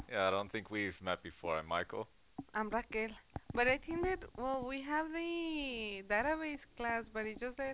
Other Non-Native Audio Examples
5. "database" (a prosodically-rich "the")